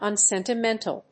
音節un・sen・ti・men・tal 発音記号・読み方
/`ʌnsènṭəménṭl(米国英語), ʌˌnsentɪˈmentʌl(英国英語)/